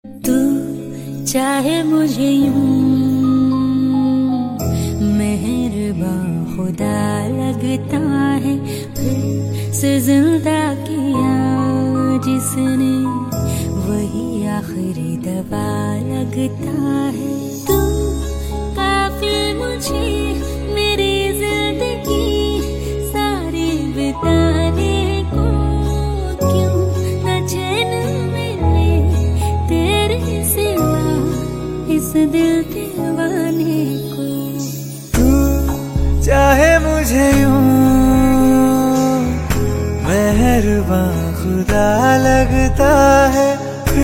Categories Hindi ringtones